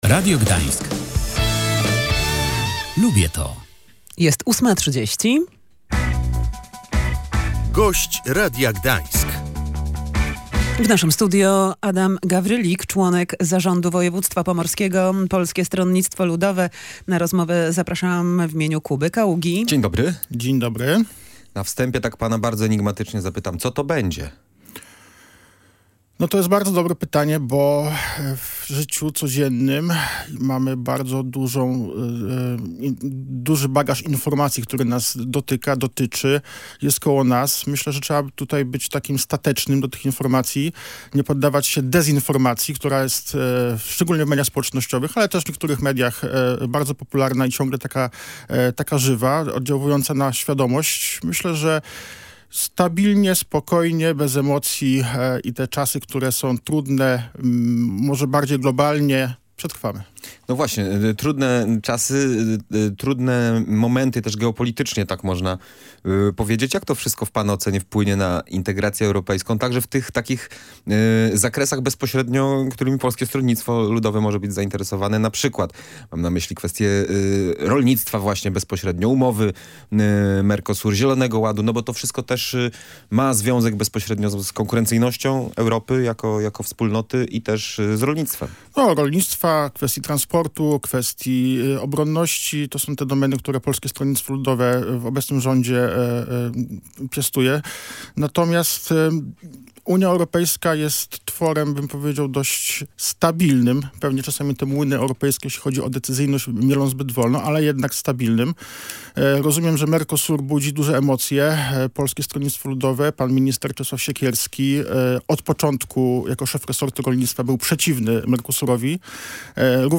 Posłuchaj całej rozmowy z Adamem Gawrylikiem, członkiem Zarządu Województwa Pomorskiego z Polskiego Stronnictwa Ludowego: https